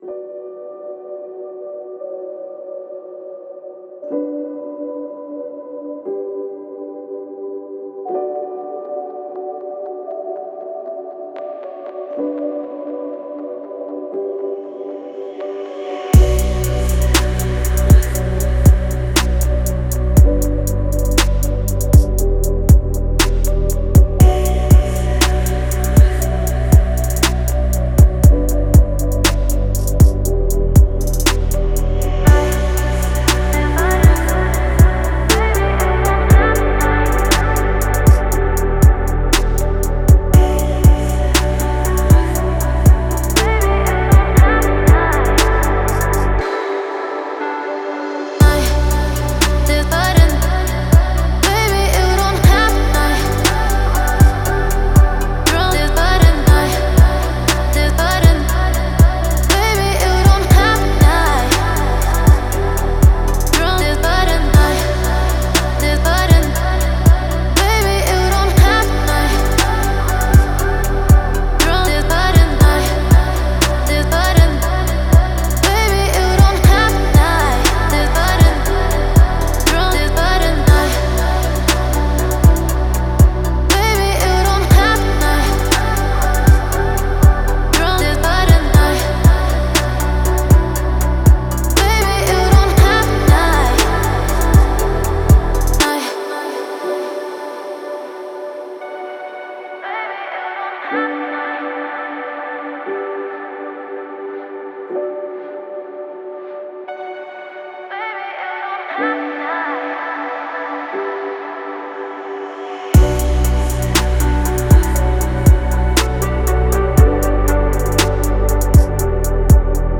Спокойная музыка
спокойные треки